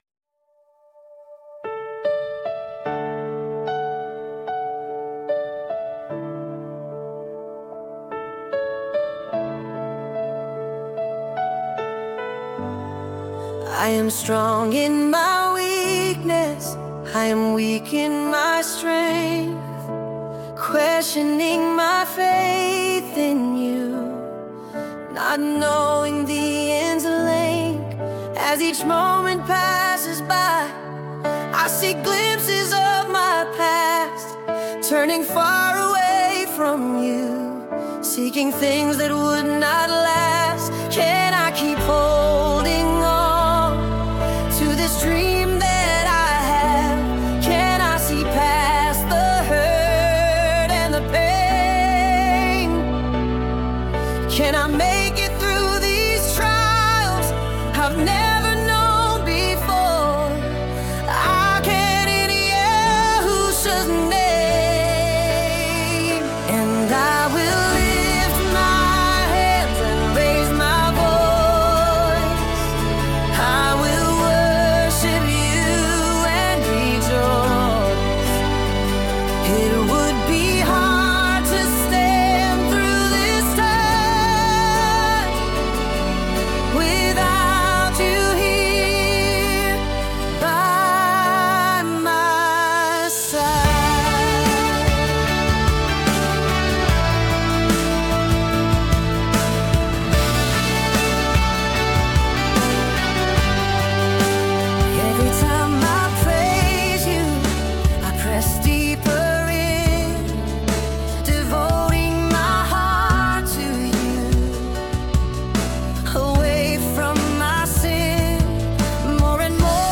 Original worship music including
• [ Practice Track – Higher Key ]